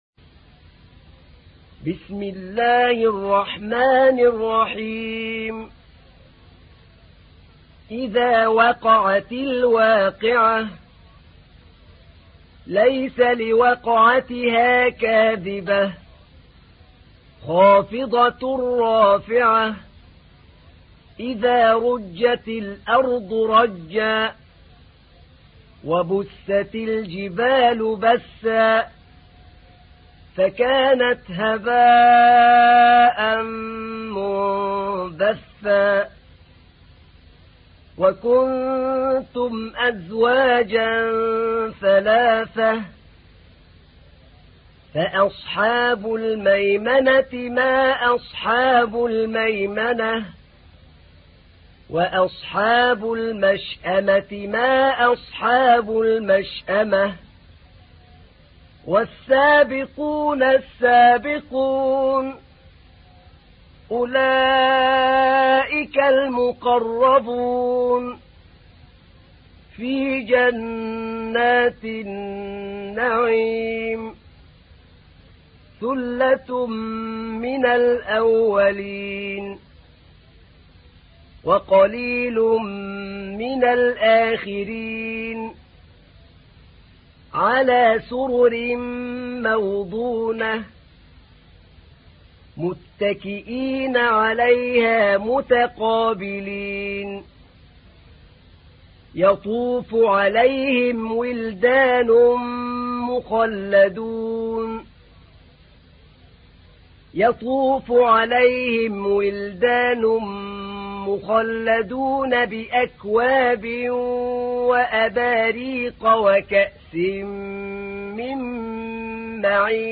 تحميل : 56. سورة الواقعة / القارئ أحمد نعينع / القرآن الكريم / موقع يا حسين